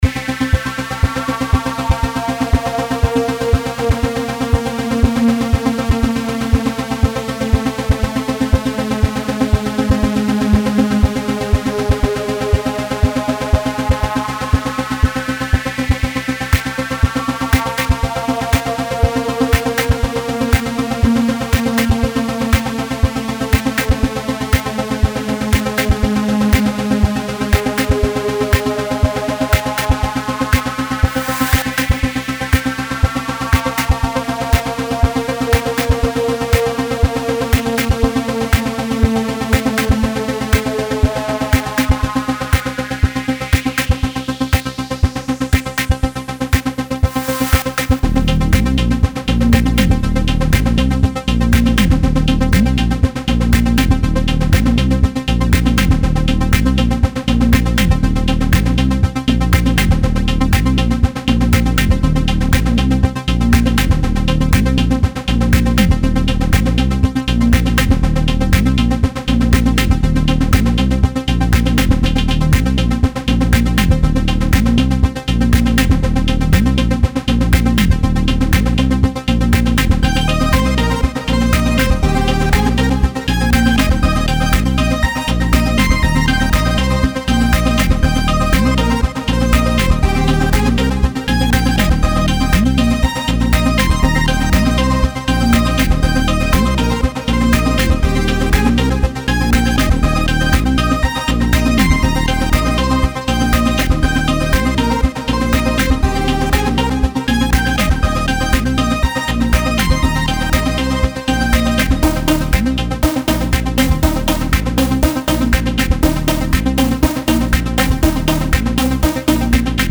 Here’s my Swarm track.